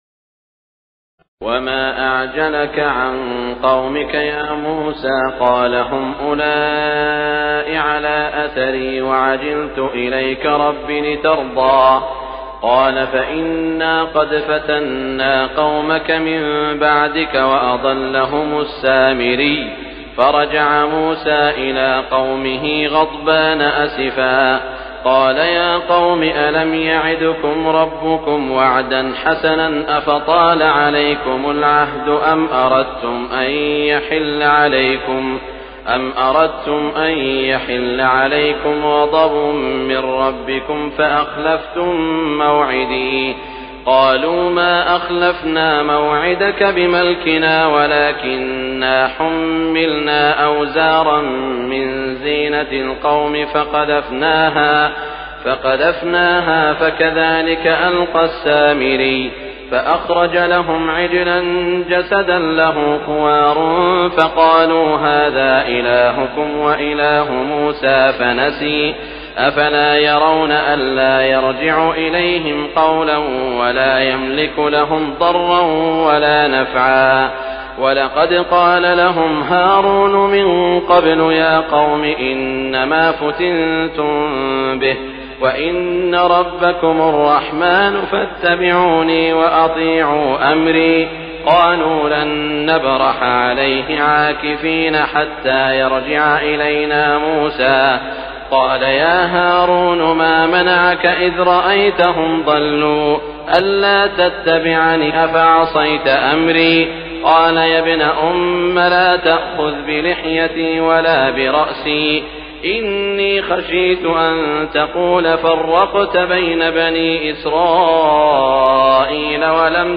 تراويح الليلة السادسة عشر رمضان 1418هـ من سورتي طه (83-135) الأنبياء (1-73) Taraweeh 16 st night Ramadan 1418H from Surah Taa-Haa and Al-Anbiyaa > تراويح الحرم المكي عام 1418 🕋 > التراويح - تلاوات الحرمين